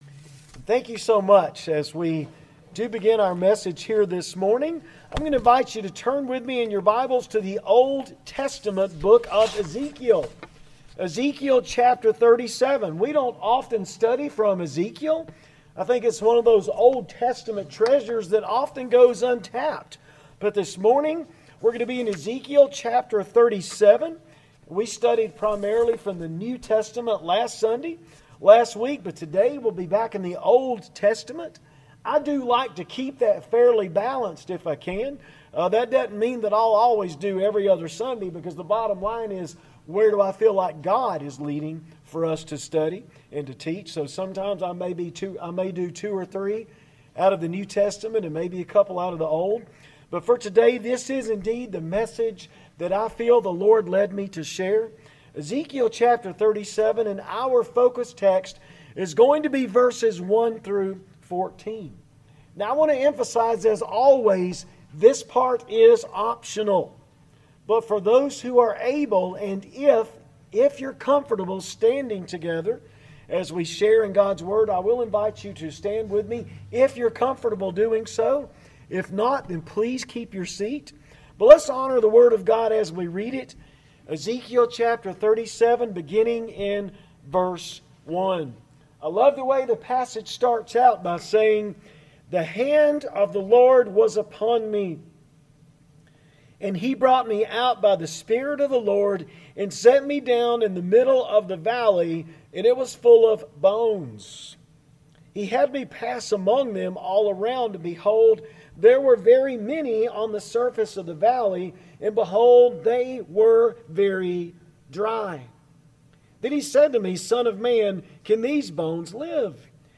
Sermons | Waleska First Baptist Church